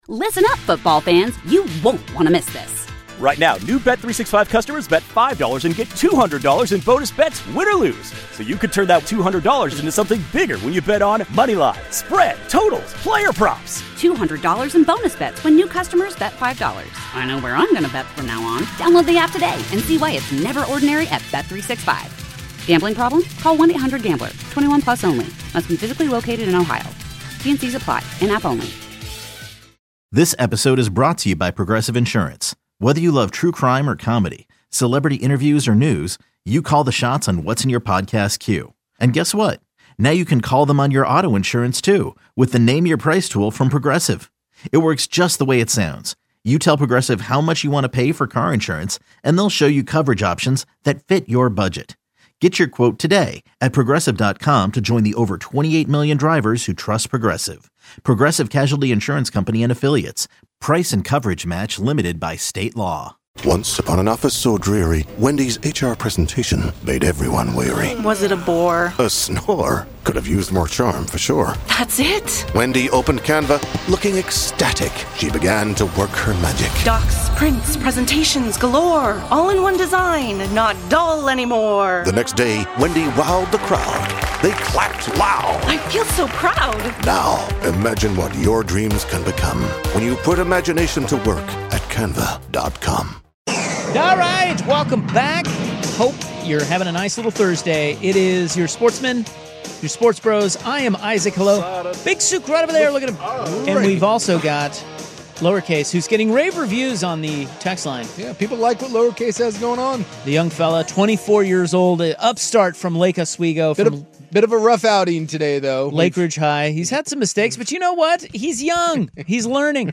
Portland's iconic sports talk show.